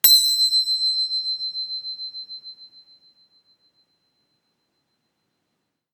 indian bell chime
angel audio bell book chime cymbal ding ending sound effect free sound royalty free Sound Effects